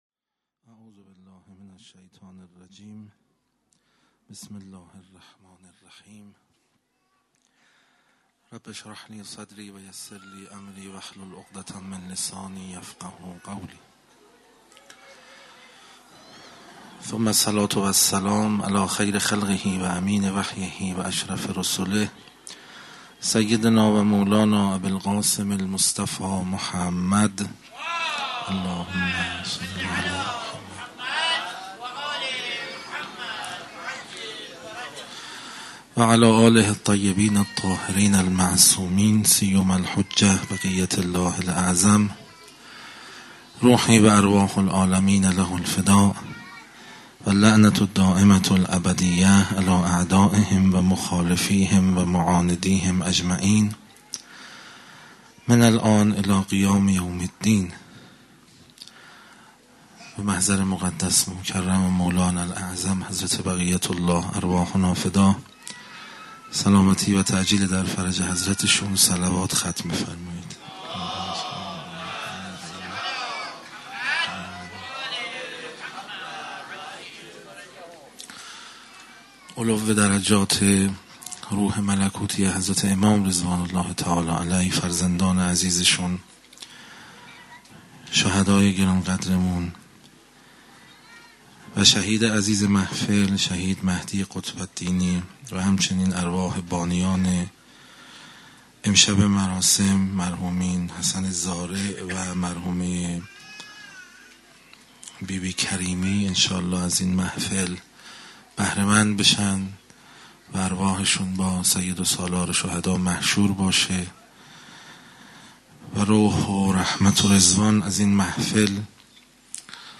سخنرانی با موضوع منطق الخیر 9 - تدبری در نامه 31 نهج البلاغه